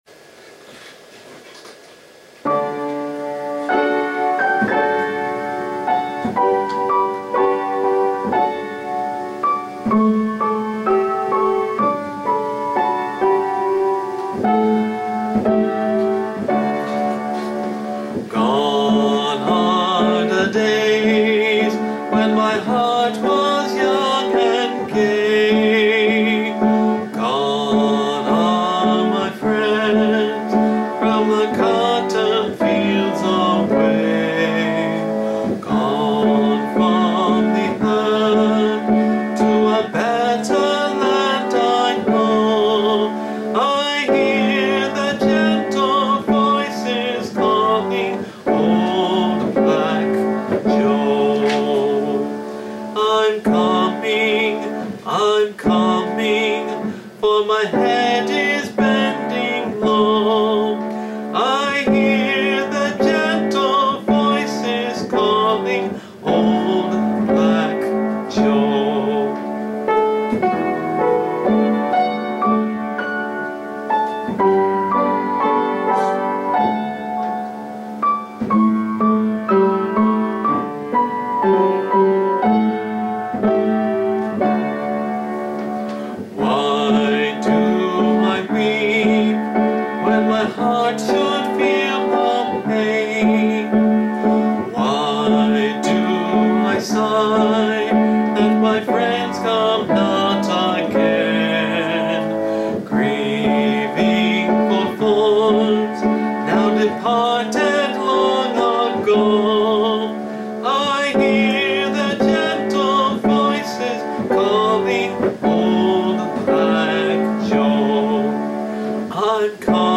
Note that the song is devoid of any affectations of black dialect.
If one can take away the bias of the modern era, indeed the century and more that has passed since its composing, and see it as a ballad telling us of the longing of the era in which it was written, one can truly appreciate the longing that goes beyond age and race.